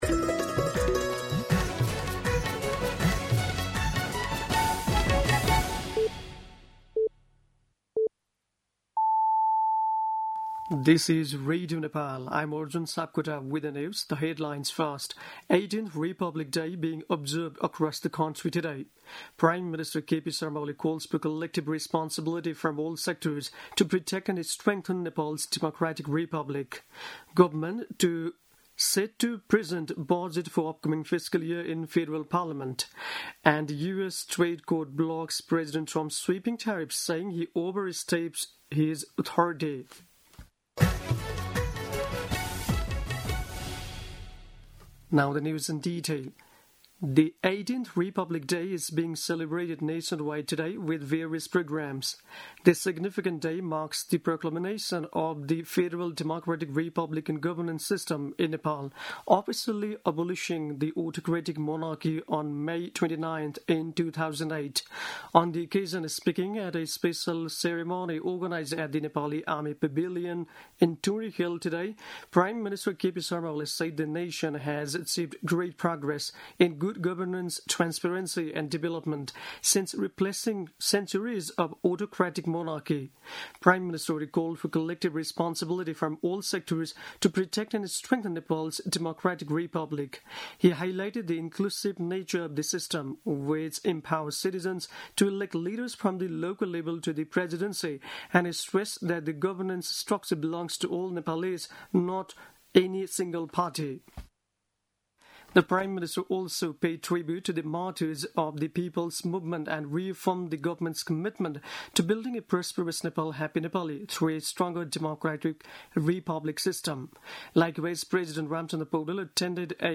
दिउँसो २ बजेको अङ्ग्रेजी समाचार : १५ जेठ , २०८२
2-pm-News-15.mp3